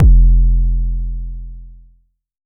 • phonk kicks oneshot 11 - 808 F.wav
Specially designed for phonk type beats, these nasty, layered 808 one shots are just what you need, can also help designing Hip Hop, Trap, Pop, Future Bass or EDM. Enjoy these fat, disrespectful 808 ...
phonk_kicks_oneshot_11_-_808_F_Jjj.wav